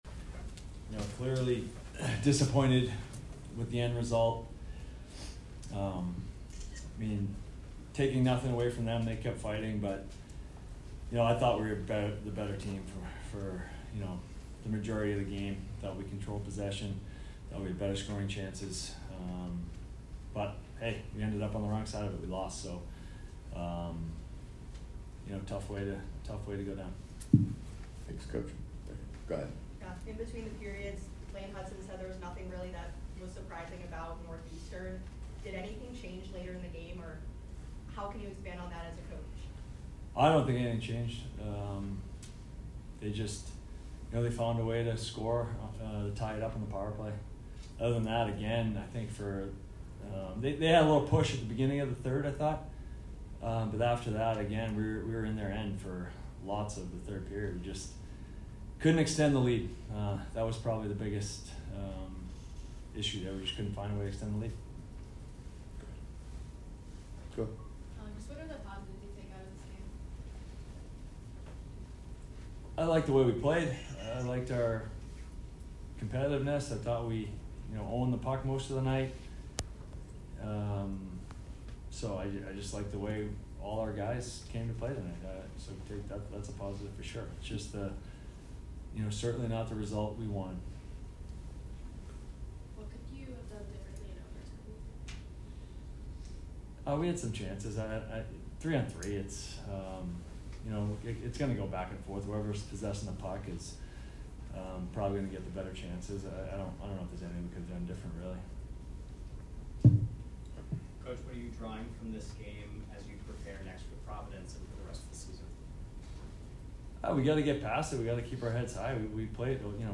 Men's Hockey / Beanpot Postgame Interview